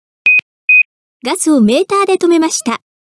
何か異常があった際に、音声でお知らせしてくれます。
ガス遮断